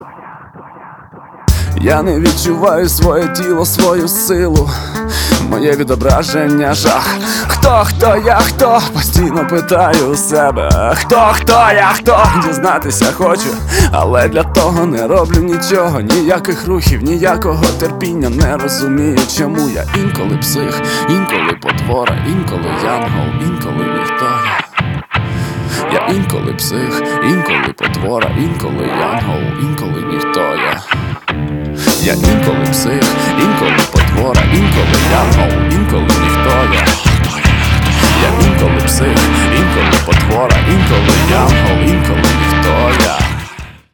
мужской вокал
громкие
Alternative Metal
nu metal
украинский рок